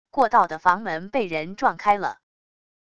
过道的房门被人撞开了wav音频